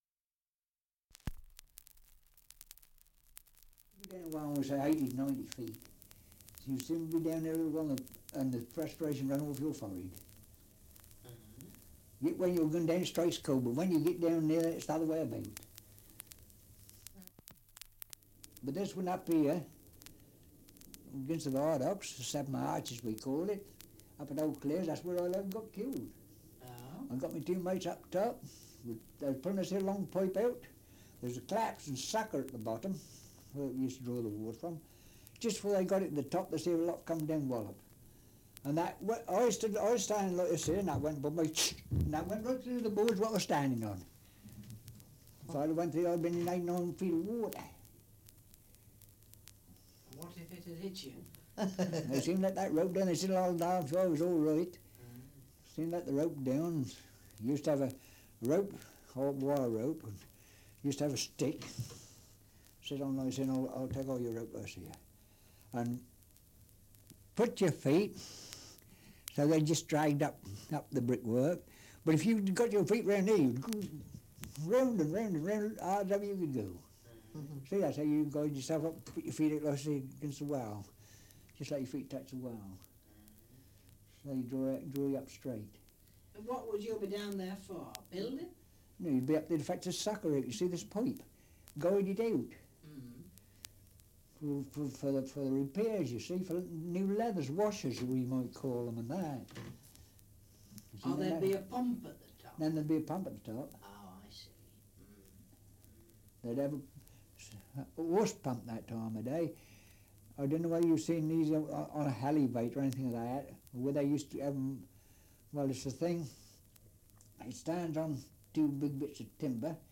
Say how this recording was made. Survey of English Dialects recording in Codicote, Hertfordshire 78 r.p.m., cellulose nitrate on aluminium